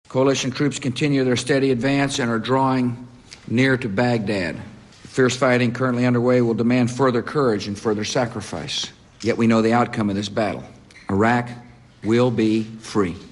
"Przeciwko temu wrogowi nie zaakceptujemy żadnego innego wyniku jak tylko całkowite zwycięstwo" - powiedział Bush na spotkaniu z weteranami w Białym Domu.
Mówi George Bush (103Kb)